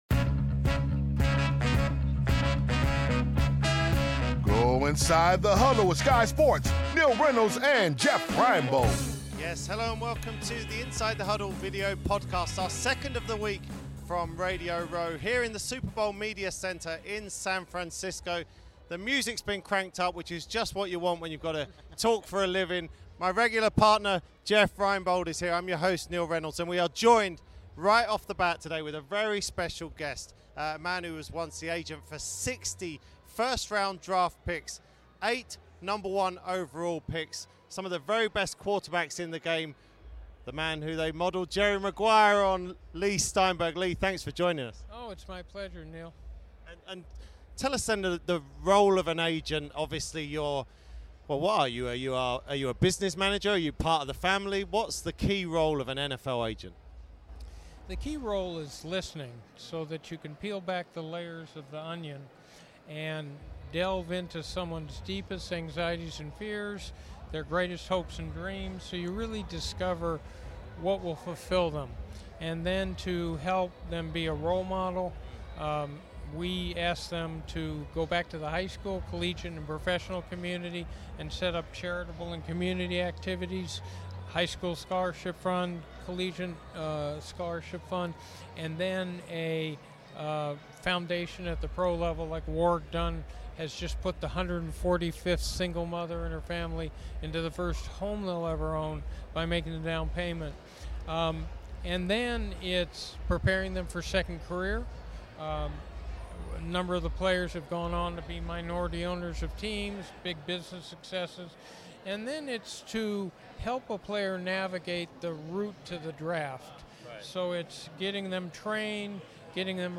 present their second podcast from Radio Row at Super Bowl 50 in San Francisco.